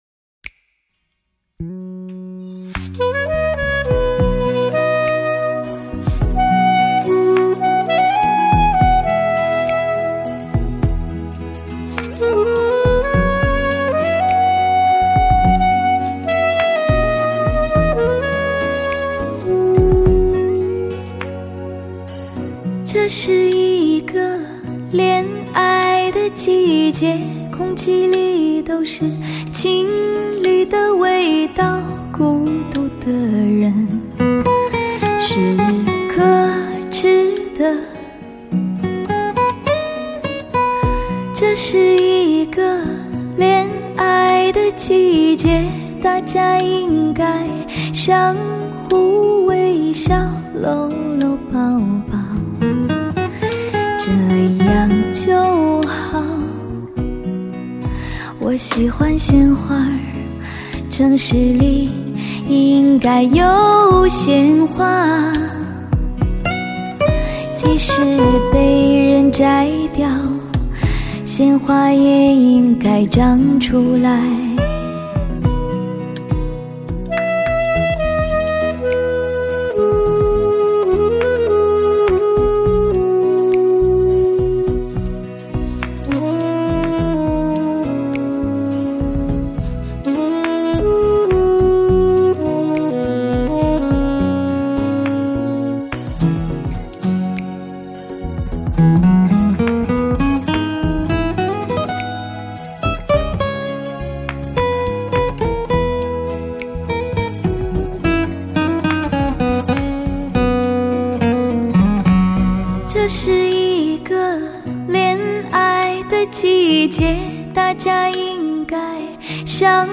STS三维高临场音效，高清录音紫水晶CD
类型: 汽车音乐